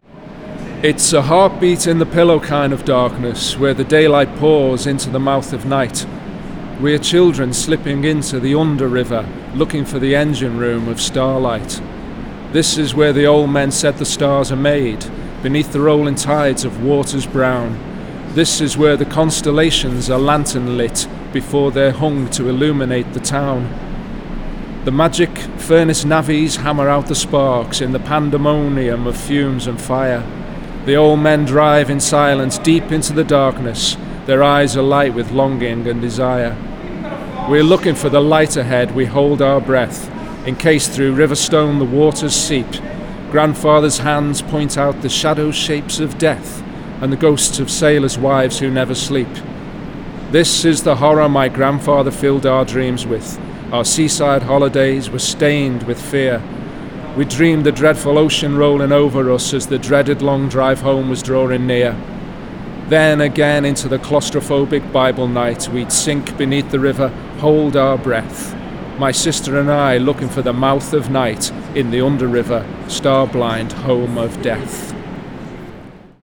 tunnel reading 2